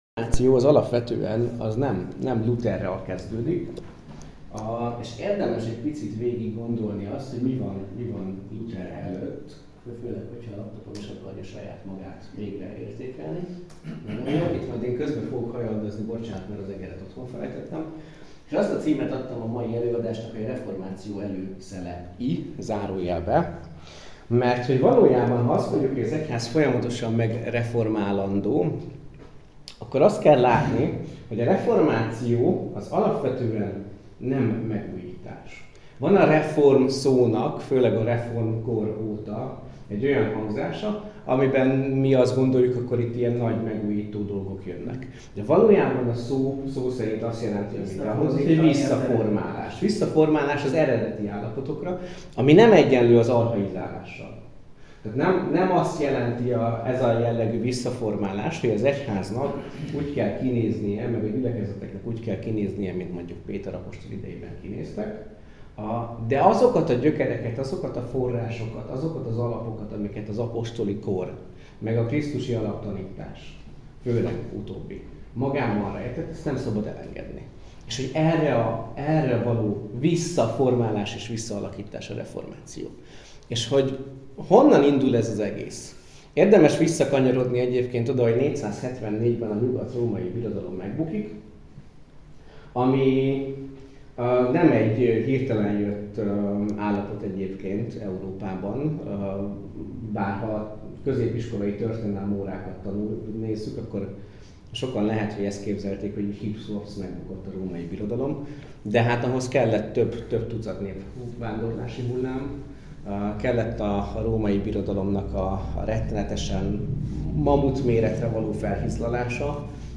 Reformáció - Felnőtt hittan Hegyeshalom